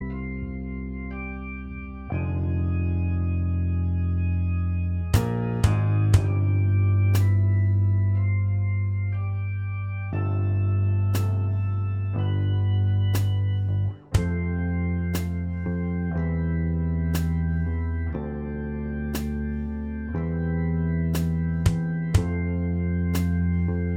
Minus Guitars Soft Rock 4:46 Buy £1.50